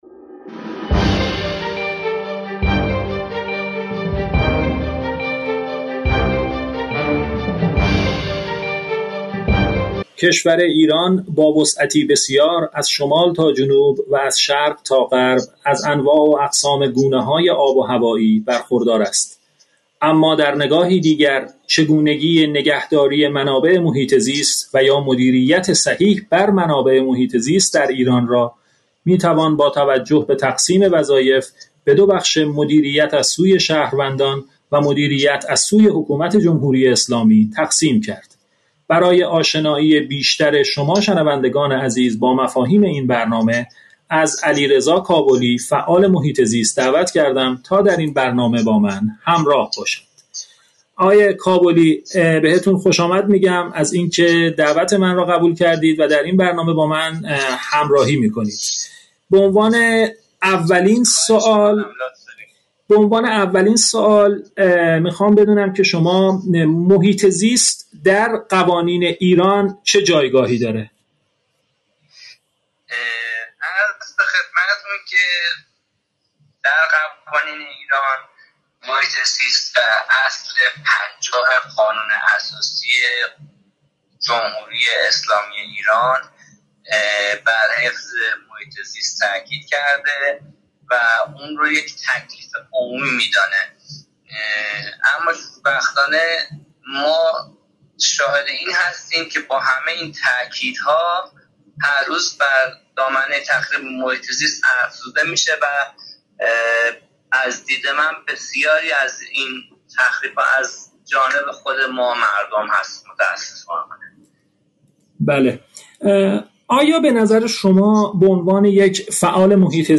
مصاحبه-پیرامون-بحرانهای-محیط-زیست-ایران.mp3